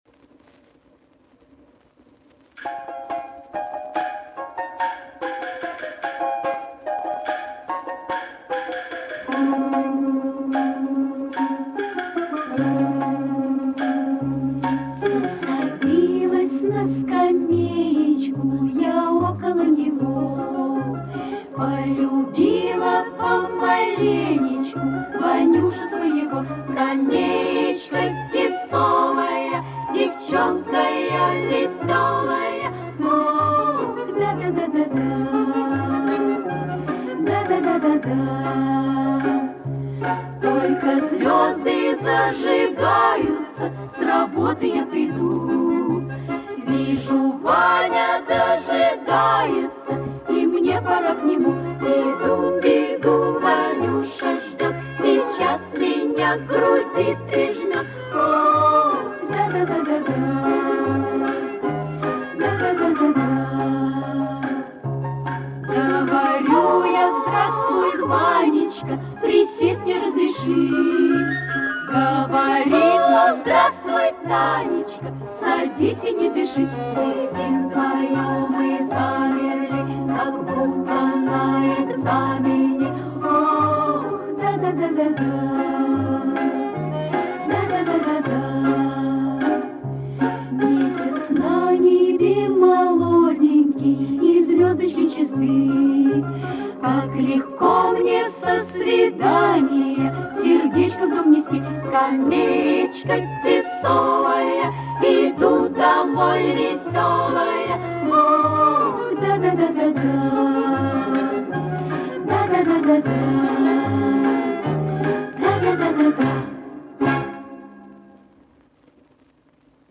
Спасибо, правда звук не очень